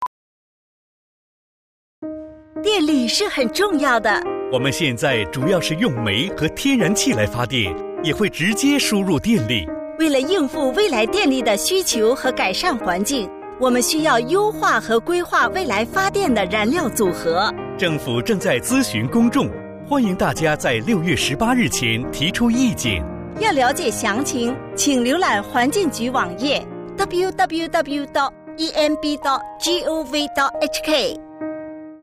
electricity_generation_radio.mp3